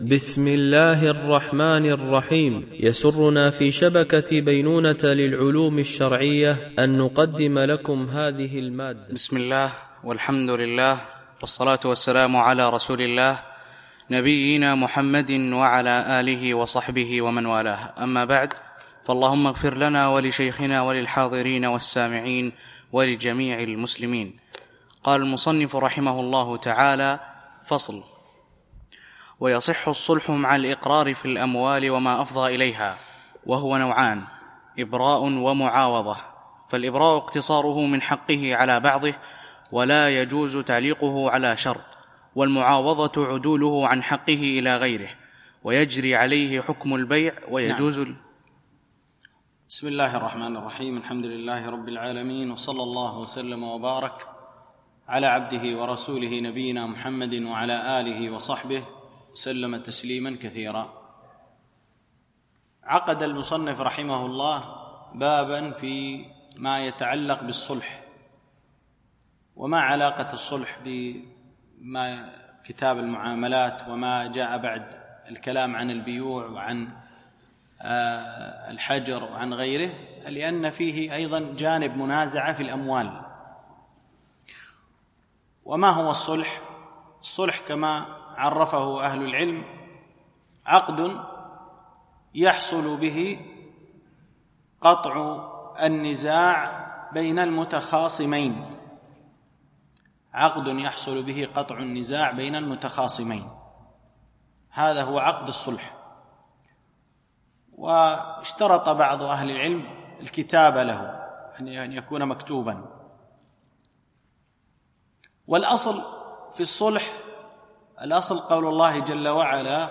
شرح متن أبي شجاع في الفقه الشافعي ـ الدرس 27